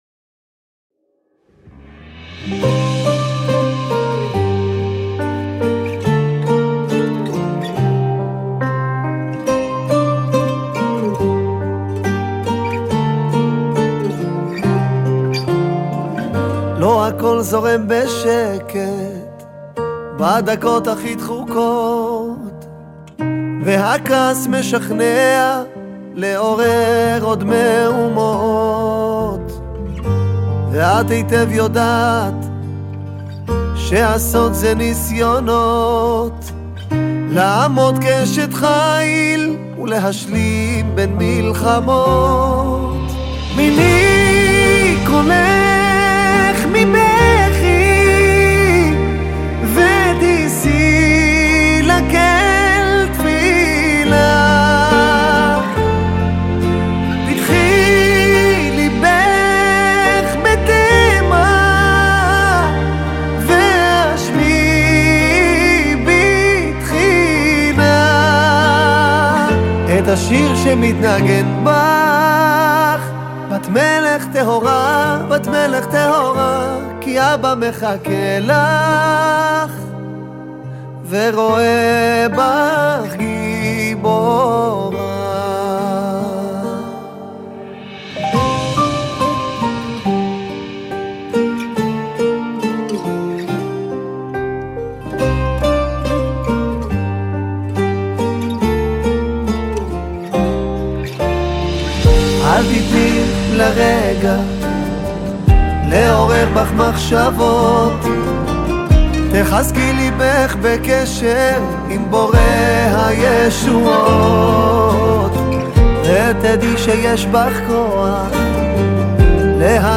דואט סוחף
הסגנון הים-תיכוני החם
ויוצר חוויה מוזיקלית עוצמתית ויוצאת דופן.